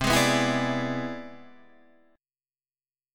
C+M9 chord